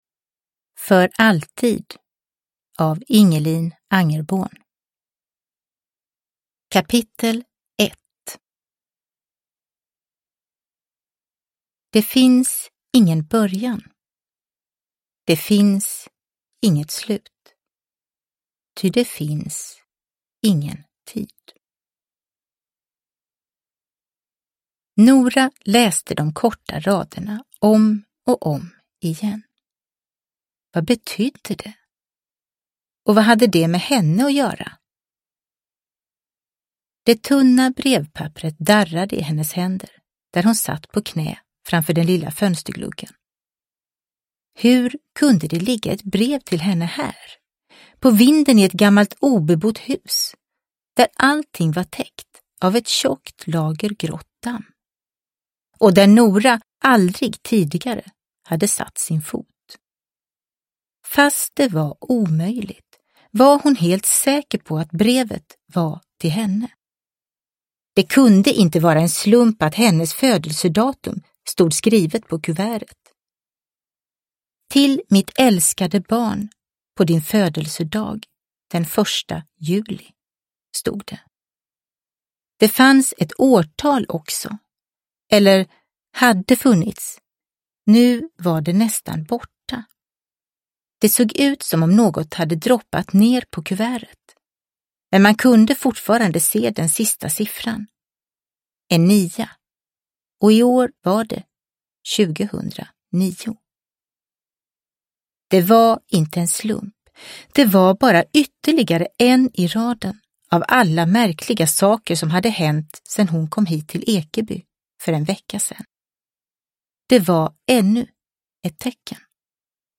För alltid... – Ljudbok – Laddas ner